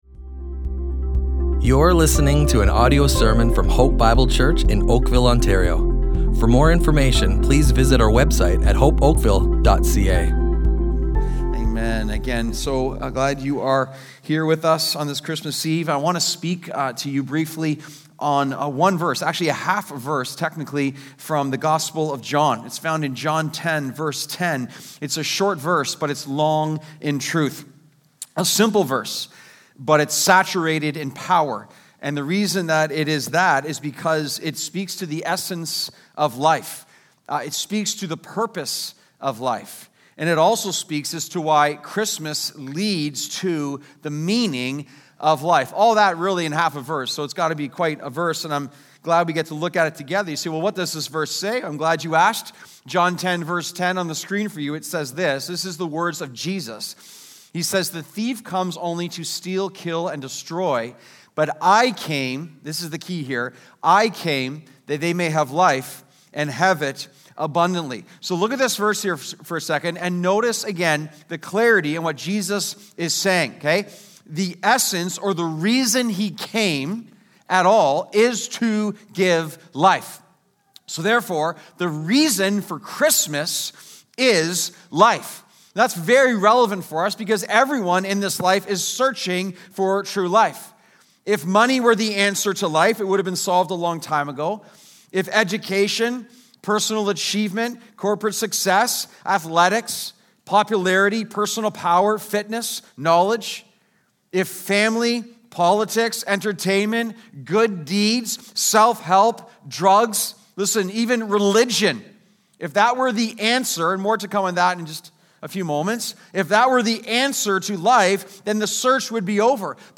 Hope Bible Church Oakville Audio Sermons Christmas Eve 2024 // He Came to Give Life!